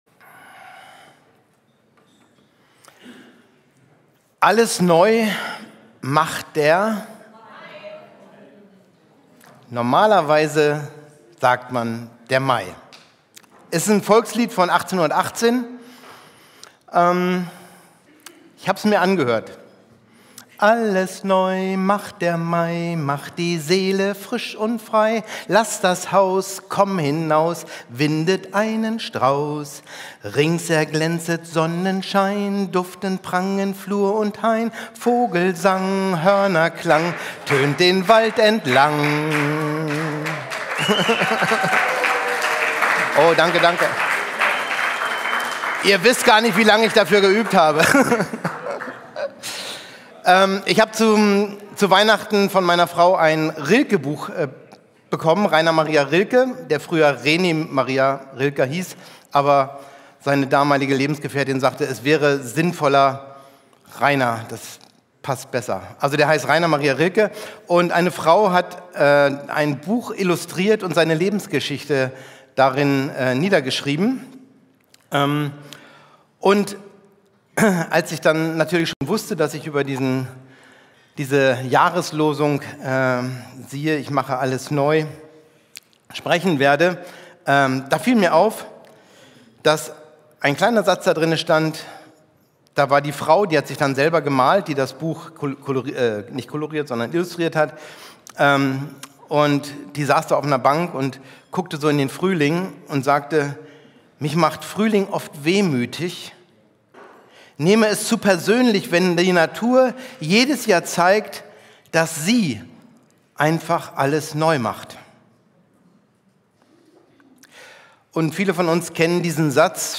Predigt mp3